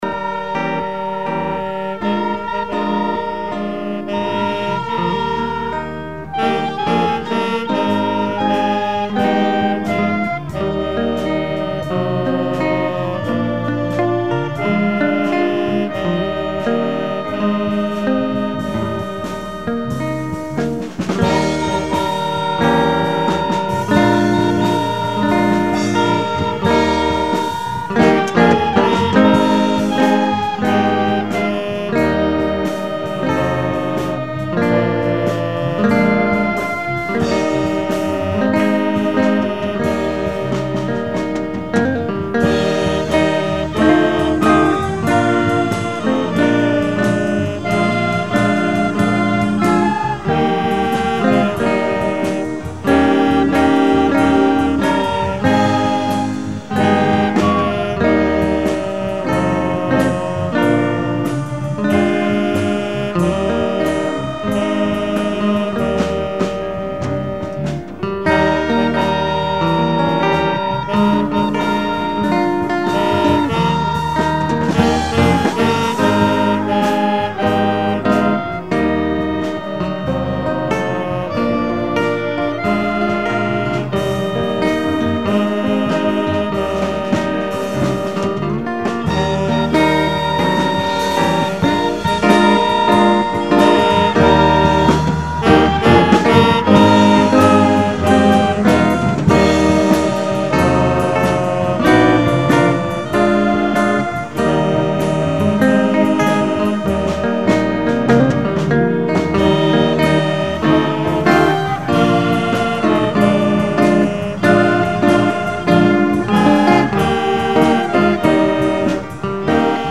Wednesday Service 3/31/10